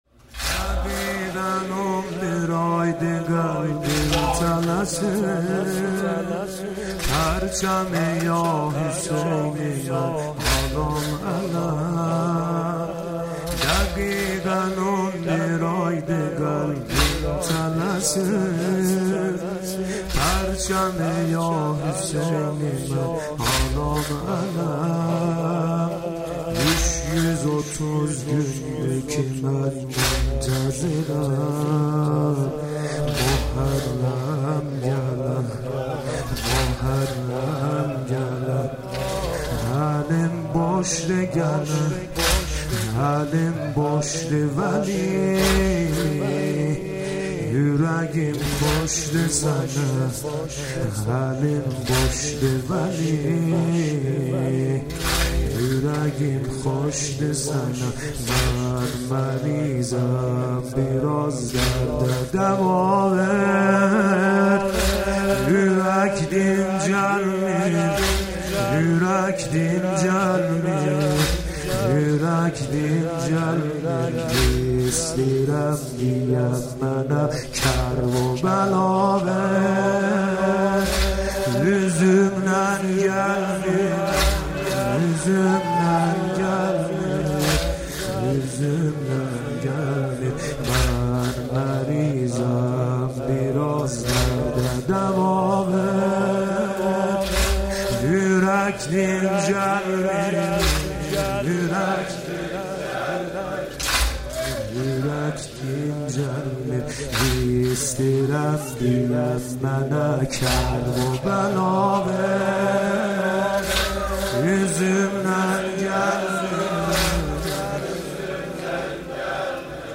مداحی دهه محرم 98